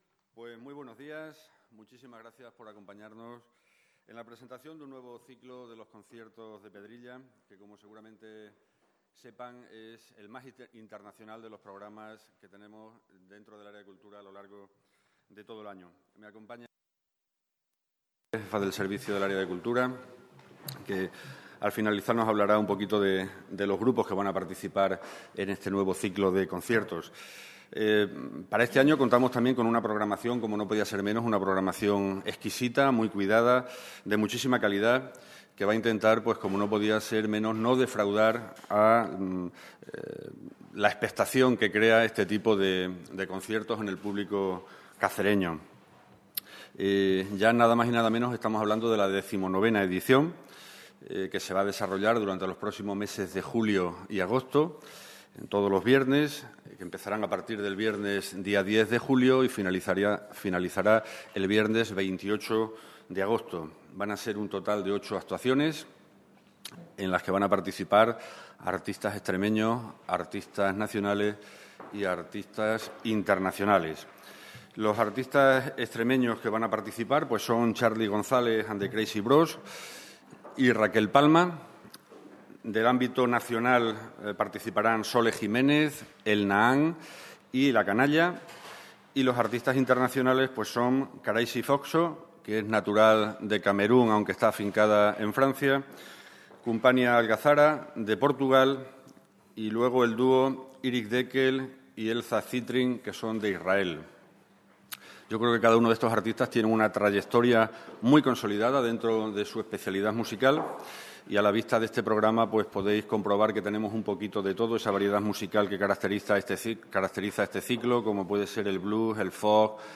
CORTES DE VOZ
ha presentado este jueves en rueda de prensa el programa de la XIX edición de ‘Los Conciertos de Pedrilla’.